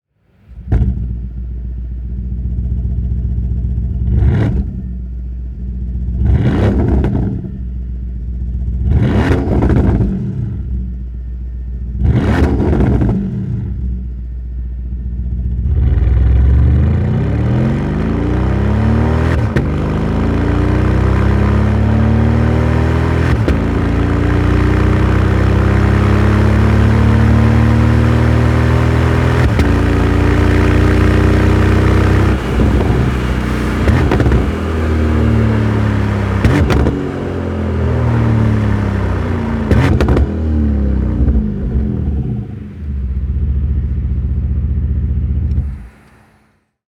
• Exhaust Audio — Slip-On Line
V8 models: Deliver a deep, orchestral harmonic rumble with a powerful exhaust note as revs climb.
Slip-On_Porsche_Panamera_V8_1.wav